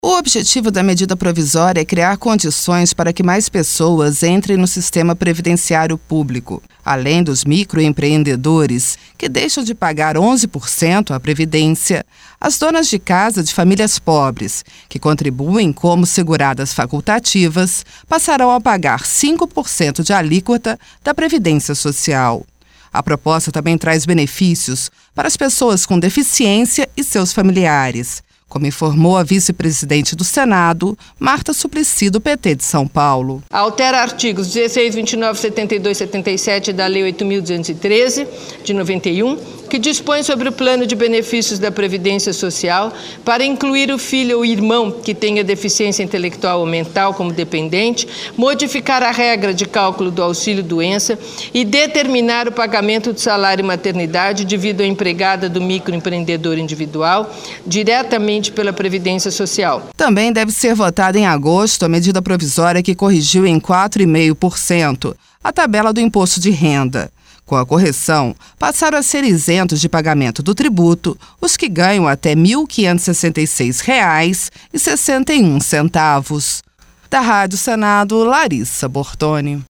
A proposta também traz benefícios para as pessoas com deficiência e seus familiares, como informou a vice-presidente do Senado, Marta Suplicy, do PT de São Paulo.